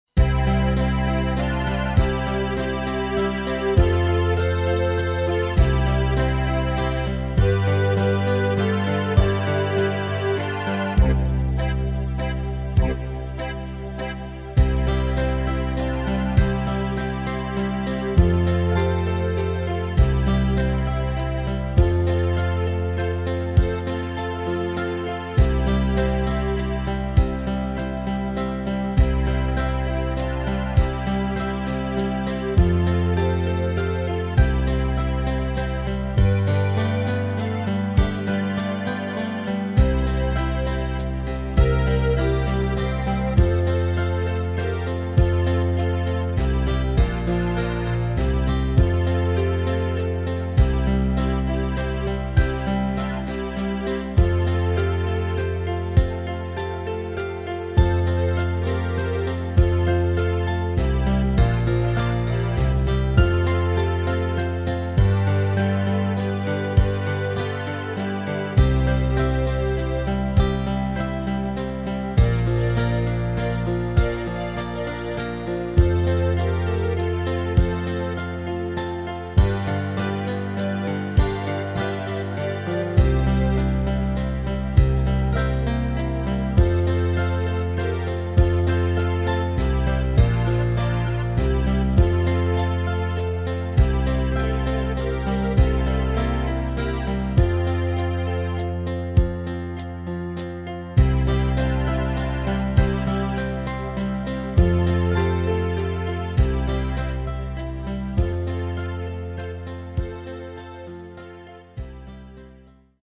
Rubrika: Pop, rock, beat
- waltz
Vlastní aranž k harmonice